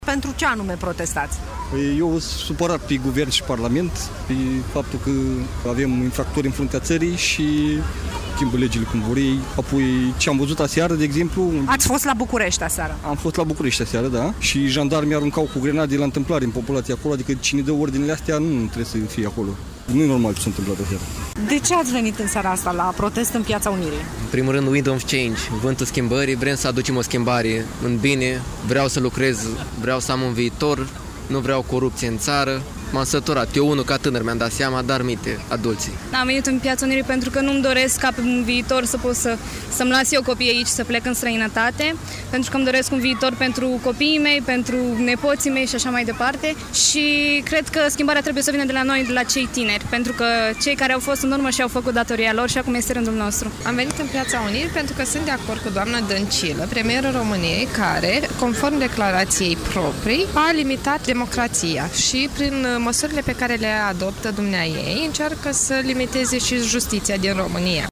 Protestatarii, de toate vârstele, au declarat că vor să poată trăi și munci în România, nu vor să fie nevoiți să plece în străinătate.
11-aug-ora-20-vox-proteste-Iasi.mp3